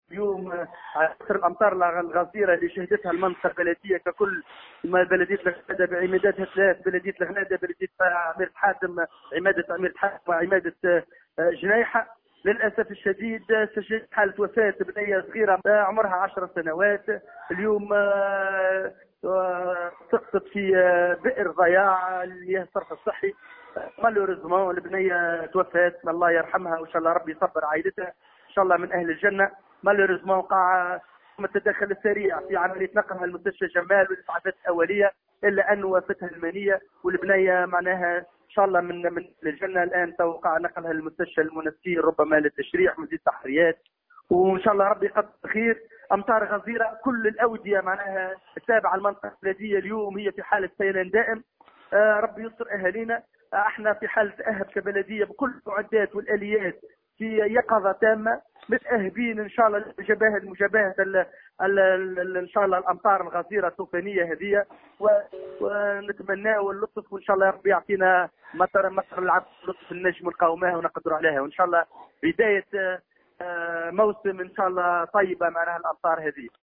ورغم التدخل السريع لفرق الإنقاذ ونقلها إلى مستشفى جمّال، إلا أن الطفلة فارقت الحياة، ليقع نقل جثتها إلى المستشفى الجامعي فطومة بورقيبة بالمنستير لعرضها على الطب الشرعي، وذلك وفق ما أكده للجوهرة أف أم، نور الدين العاشق، رئيس بلدية الغنادة.